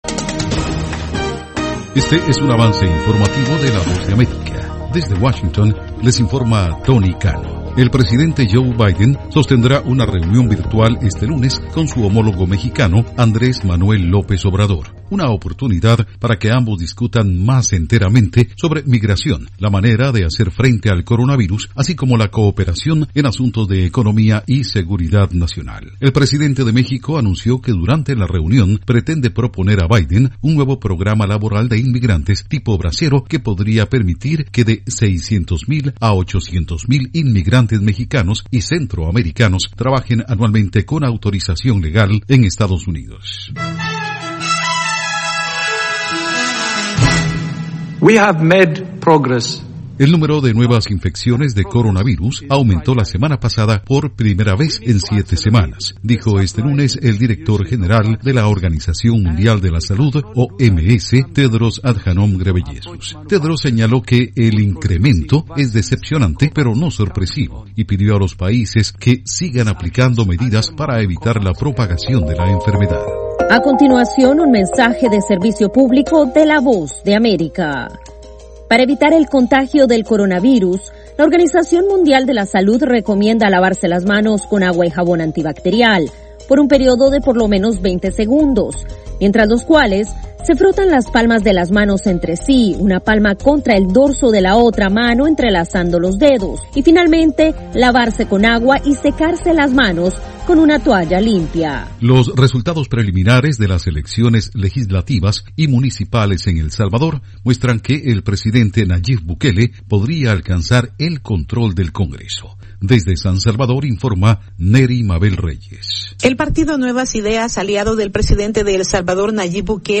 Cápsula informativa de tres minutos con el acontecer noticioso de Estados Unidos y el mundo